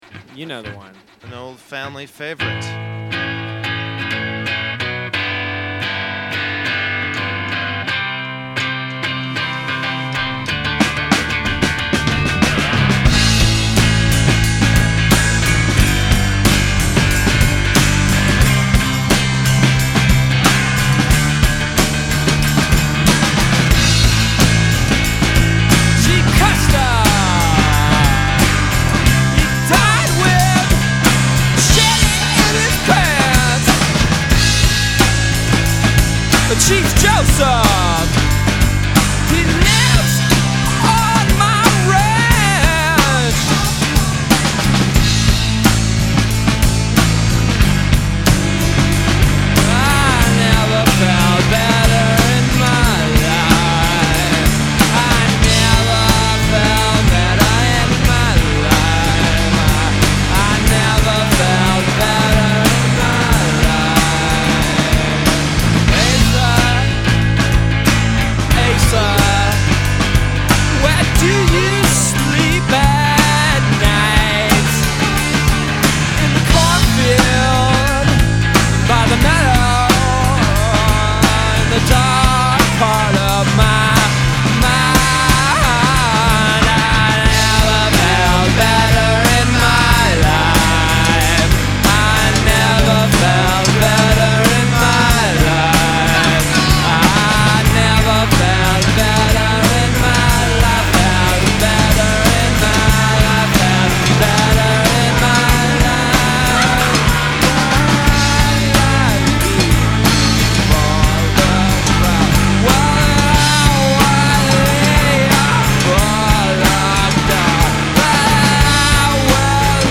here’s a live cut